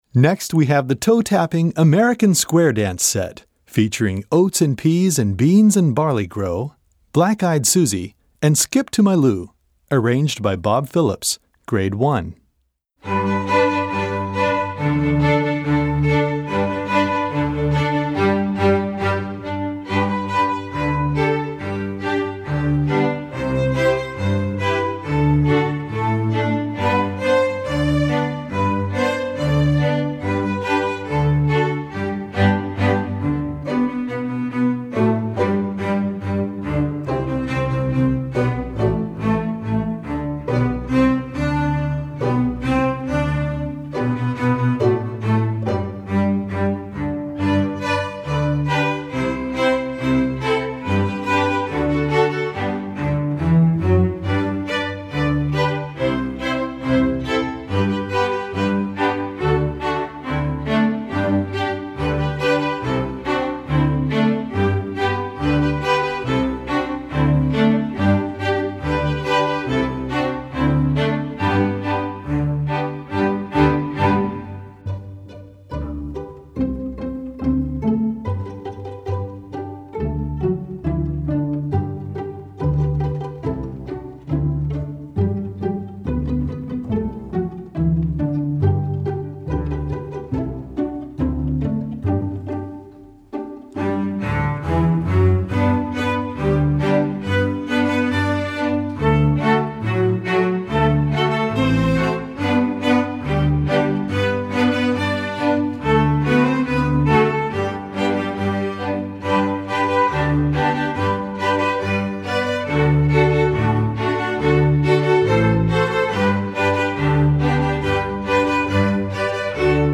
Komponist: Traditionell
Gattung: Streichorchester
Besetzung: Streichorchester
This easy, fun tune will delight audiences and students.